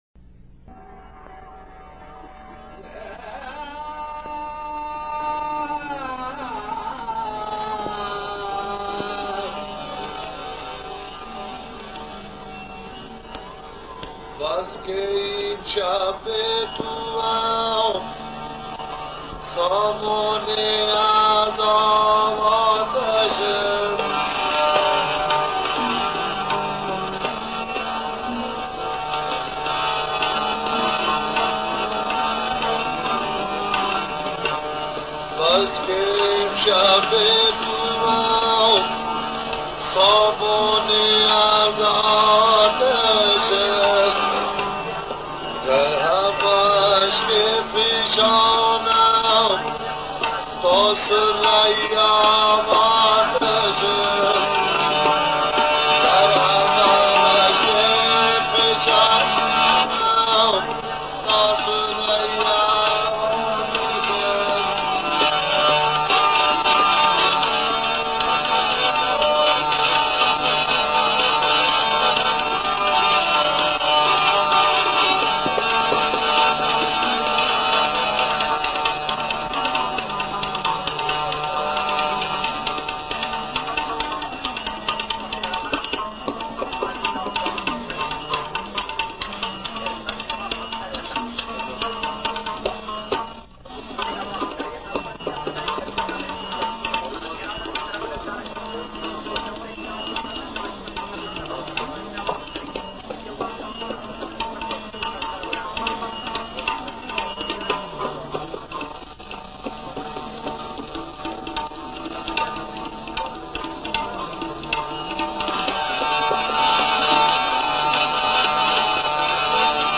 آهنگ ناتمام است و فقط یک بیت از غزل در آن خوانده شده است همراه با یک بیت شاهد.